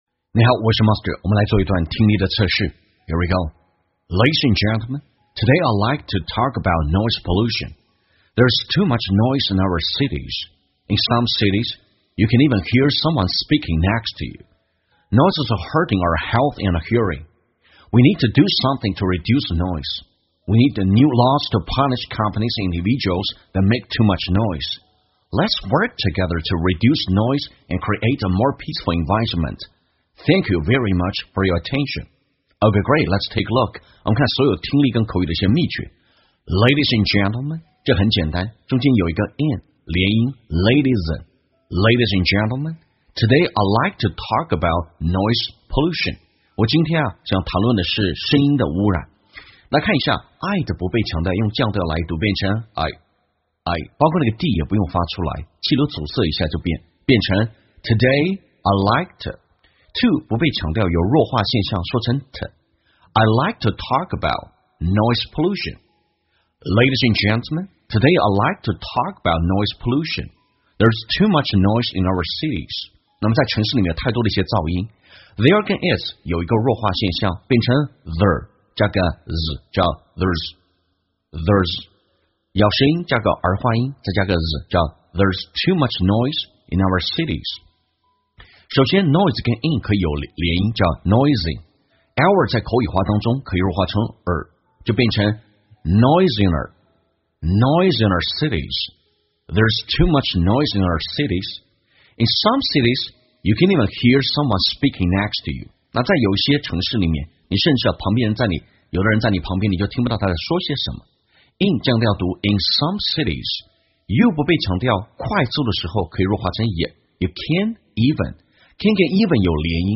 在线英语听力室瞬间秒杀听力 第530期:噪音污染的听力文件下载,栏目通过对几个小短句的断句停顿、语音语调连读分析，帮你掌握地道英语的发音特点，让你的朗读更流畅自然。